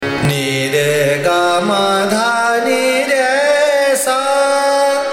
Aroha‘N r G M D N r S